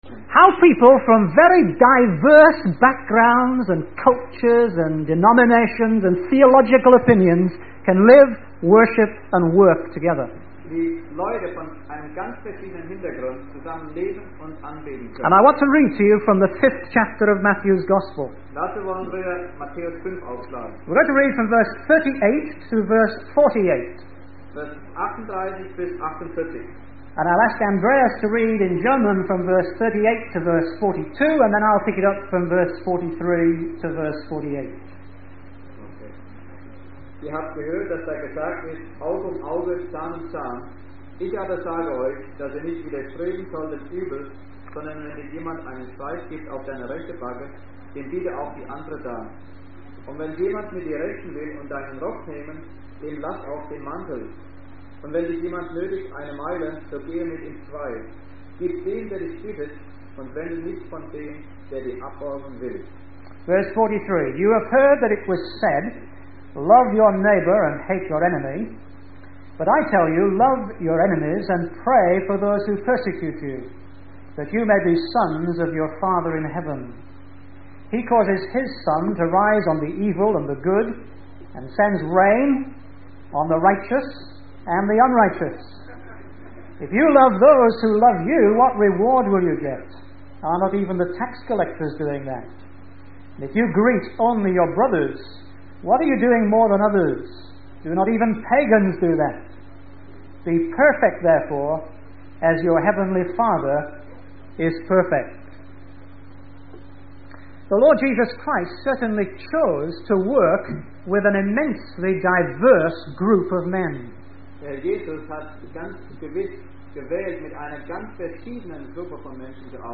In this sermon, the speaker discusses four principles taught by Jesus to his disciples.